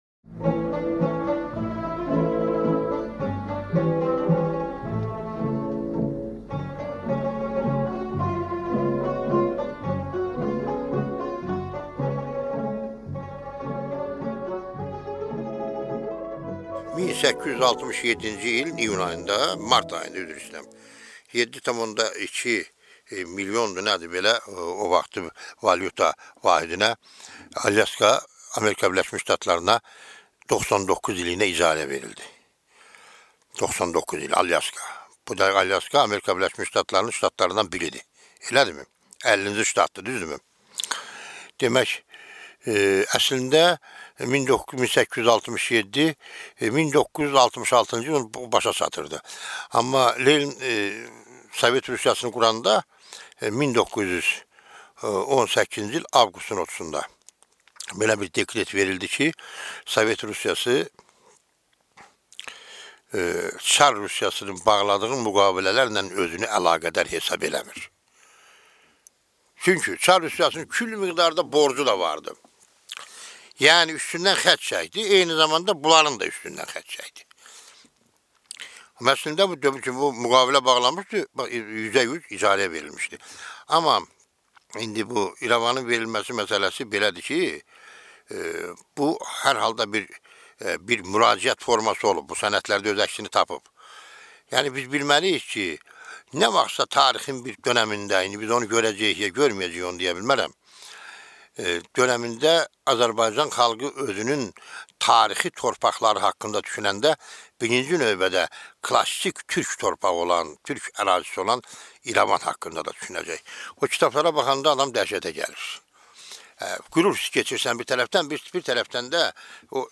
Аудиокнига Azərbaycanın İstiqlal Bəyannaməsi 1918-ci il | Библиотека аудиокниг